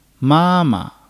ma1-ma.mp3